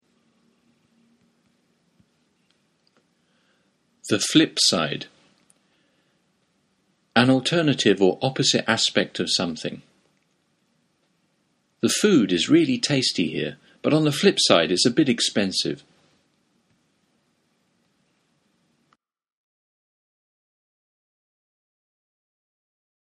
ネイティブによる発音は下記のリンクをクリックしてください。